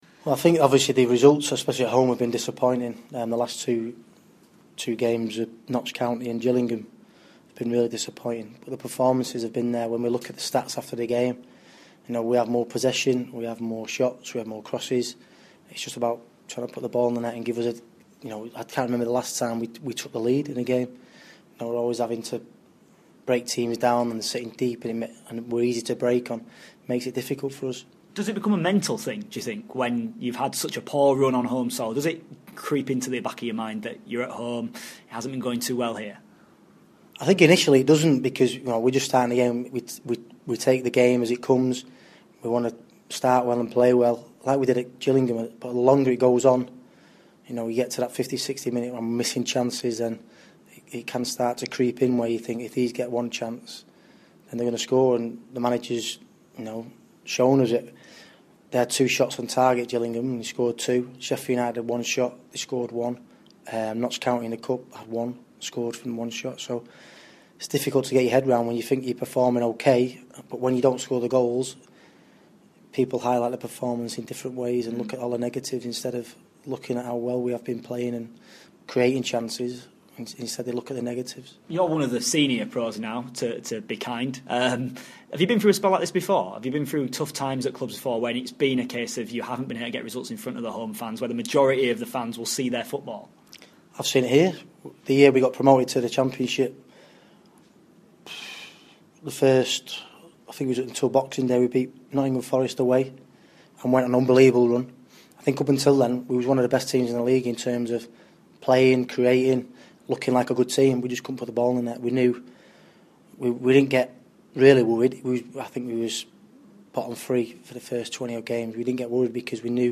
INTERVIEW: Doncaster midfielder Richie Wellens on Rovers underachievement so far this season: